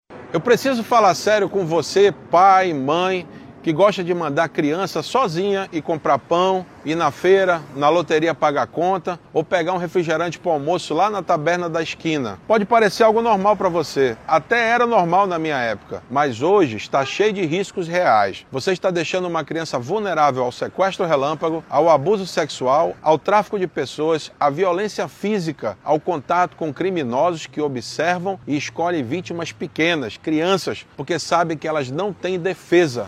A prática já foi comum no passado, mas hoje representa um perigo real, como afirma o diretor do DPI, delegado Paulo Mavignier.
SONORA-01-DELEGADO-1-1.mp3